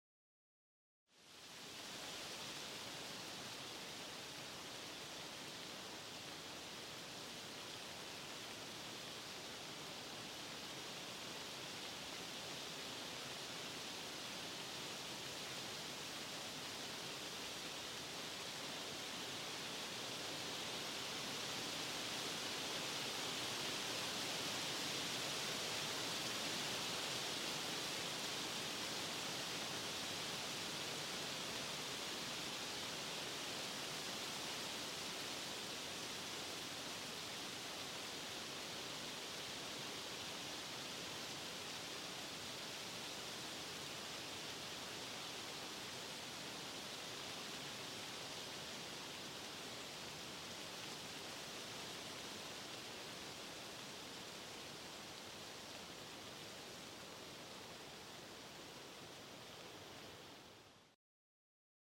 Категория: Ветер
Средний ветер, к концу файла затихает — 01 мин
Здесь небольшая подборка звуков легкого движения воздуха.